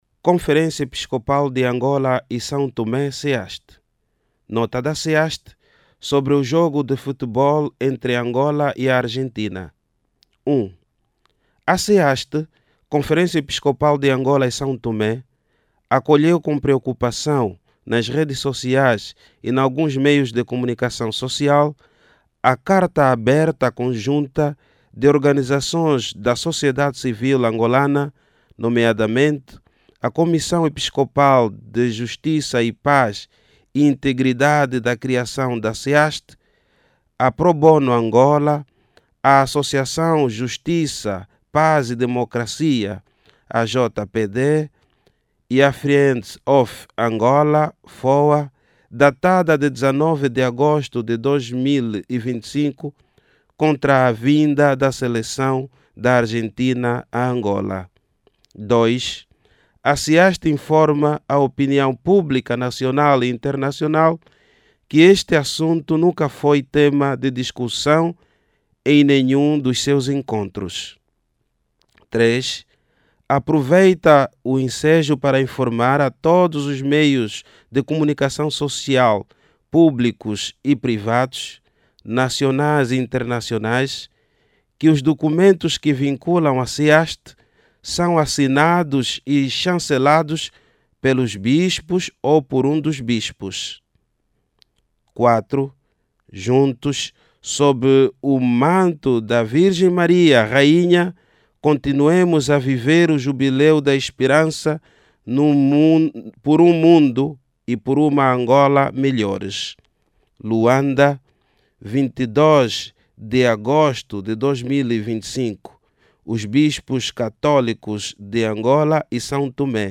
Oiça o comunicado